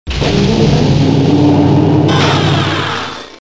TNG-Hollow Deck Door